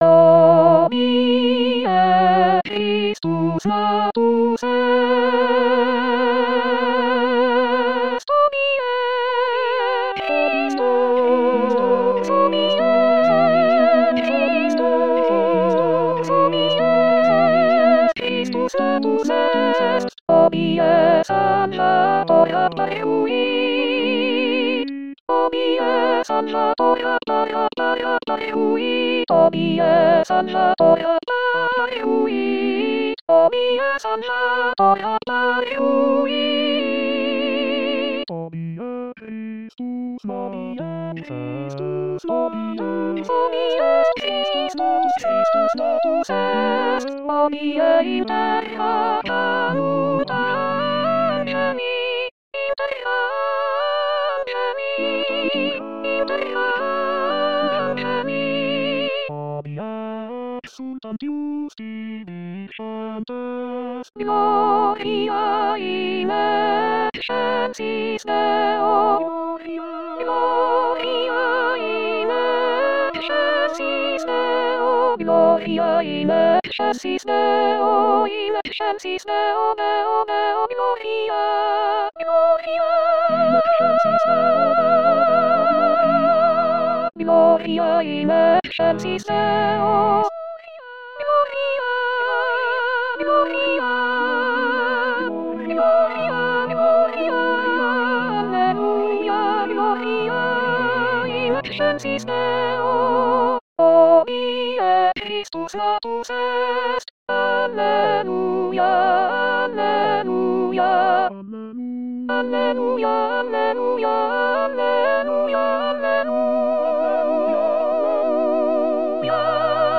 Soprano Soprano 2